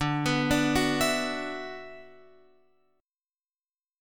D6add9 chord